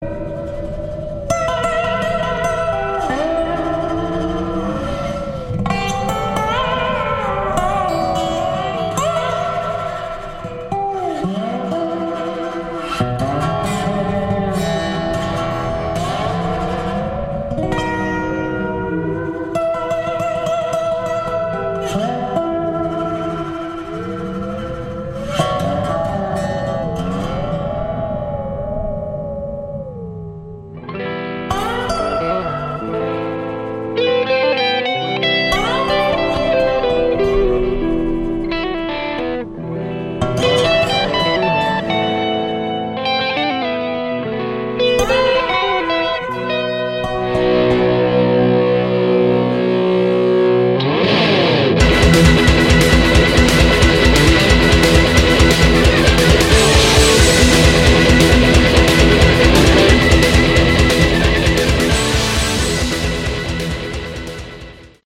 Category: Hard Rock
Drums
Bass
Guitar
Guitar, Keyboards
Vocals